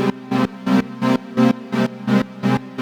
Index of /musicradar/sidechained-samples/170bpm